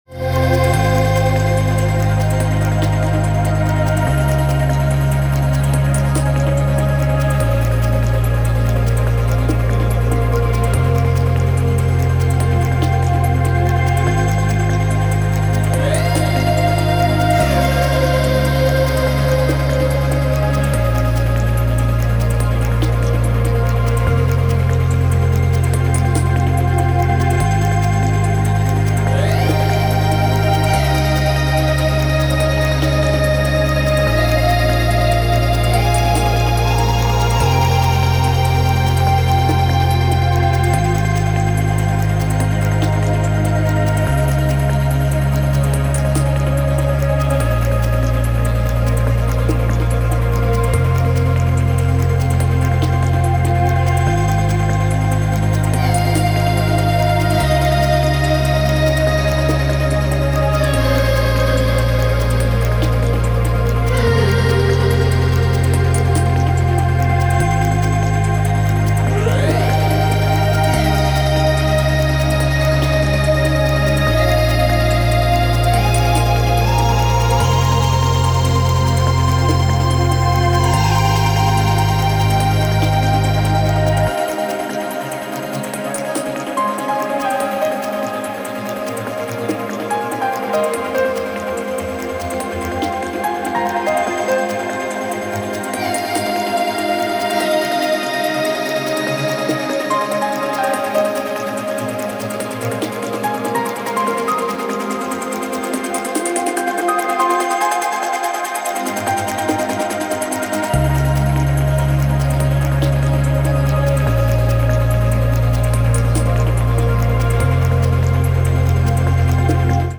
Techno , Trance